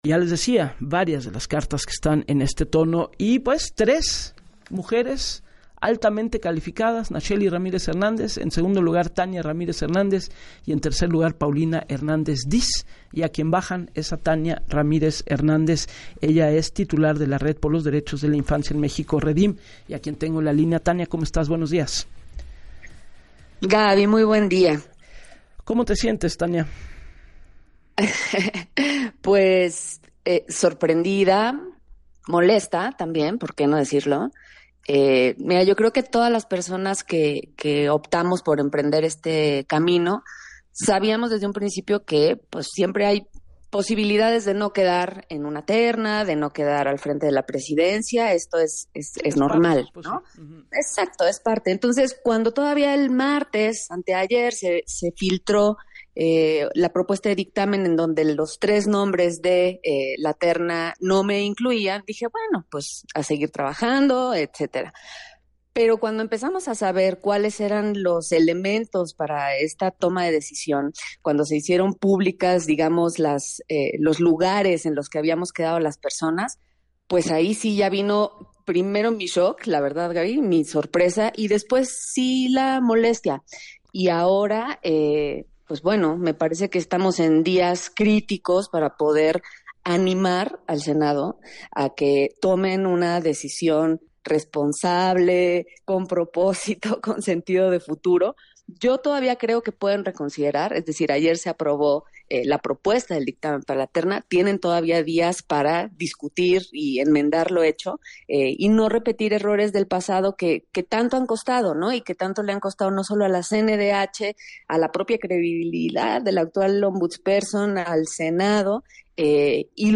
En el espacio de “Así las Cosas” con Gabriela Warkentin, hizo un llamado al consenso de los partidos, pero respetando el procedimiento.